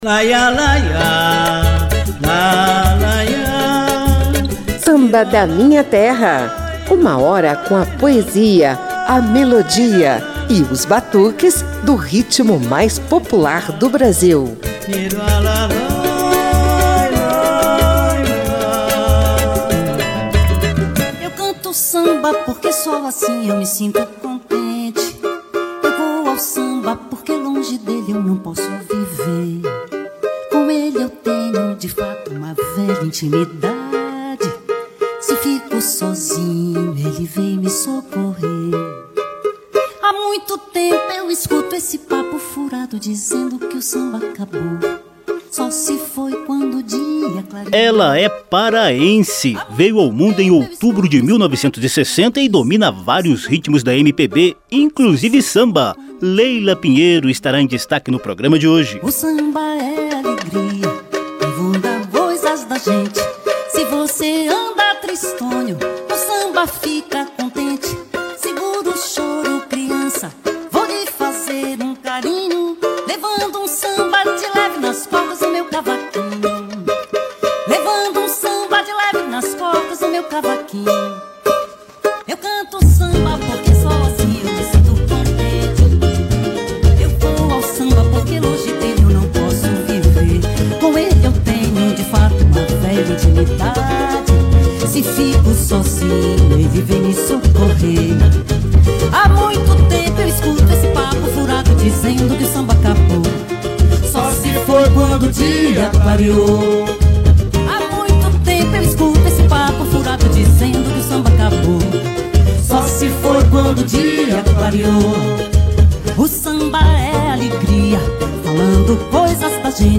com muito batuque e interpretação requintada.
a cantora paraense